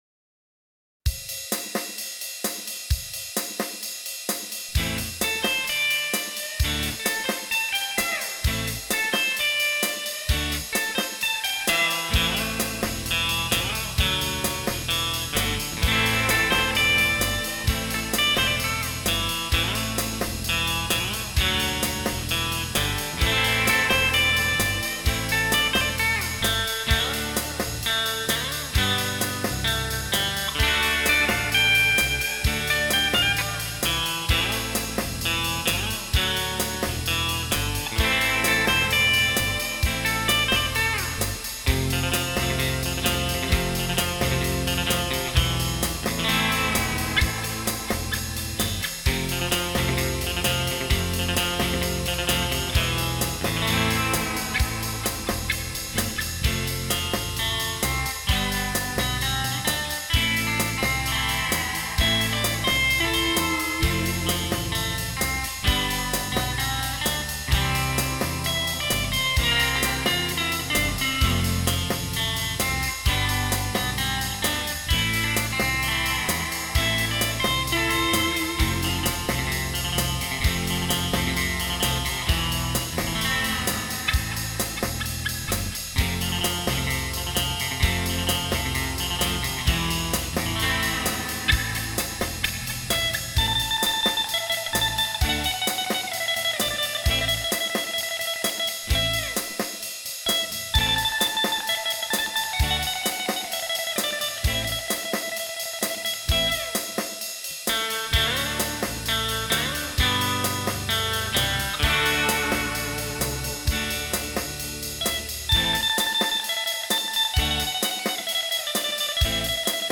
And a few more recorded directlly from the Palmer